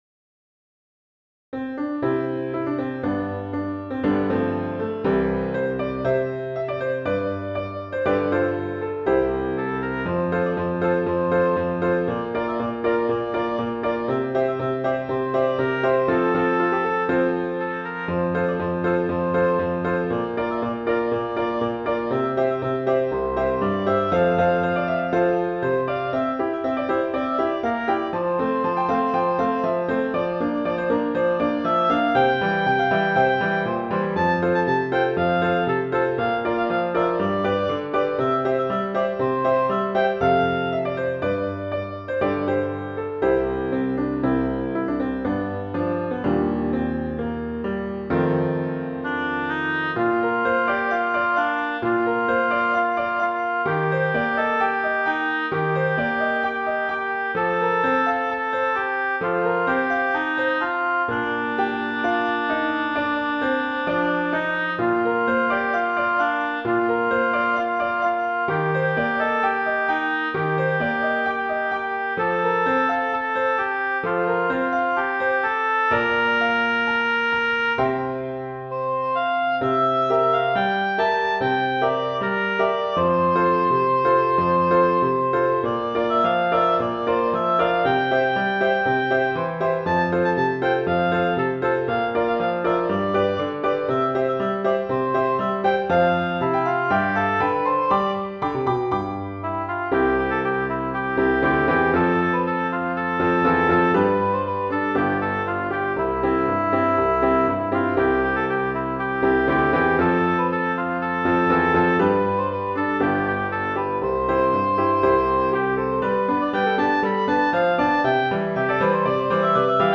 Intermediate Instrumental Solo with Piano Accompaniment.
Christian, Gospel, Sacred.
set to a fast past, energetic jig.